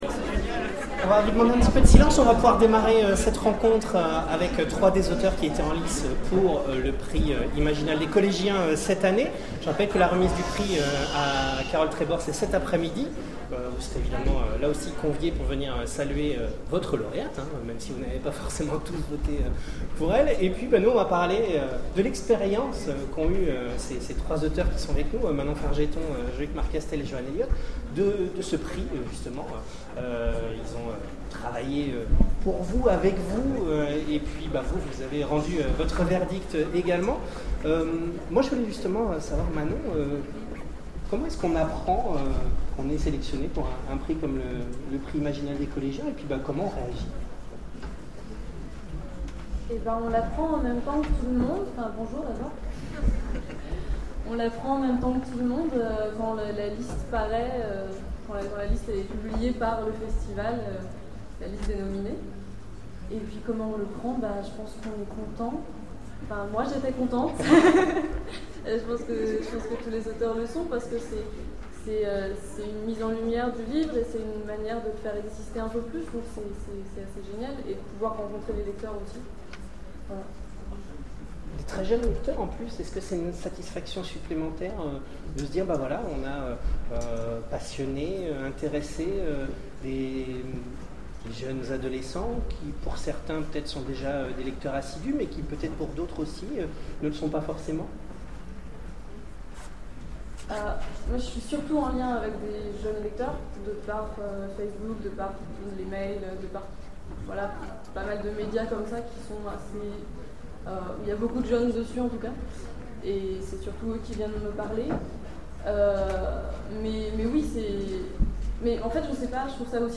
Imaginales 2013 : Conférence Prix Imaginales des collégiens
Conférence
Mots-clés Rencontre avec un auteur Conférence Partager cet article